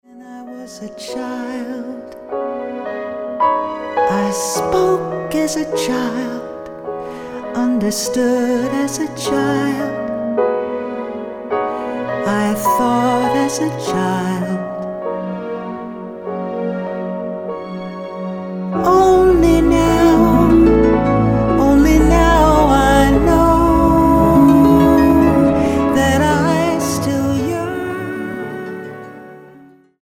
Schlagzeug
Perkussion